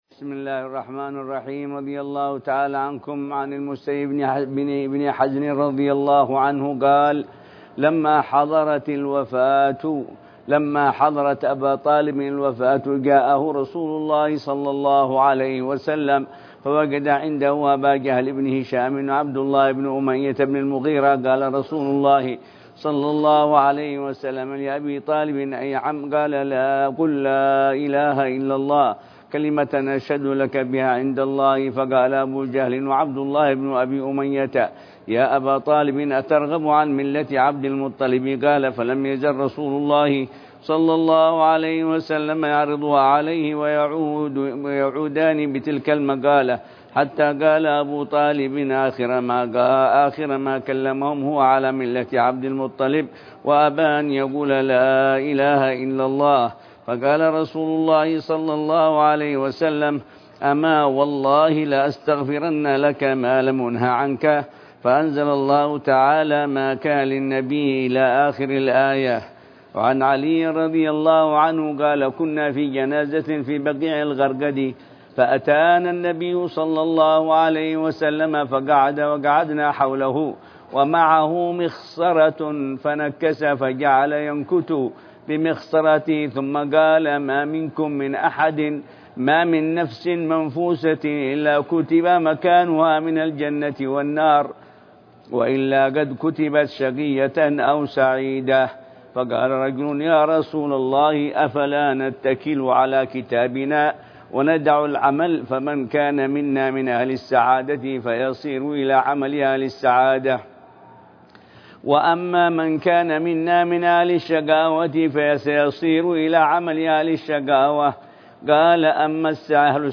دروس الحديث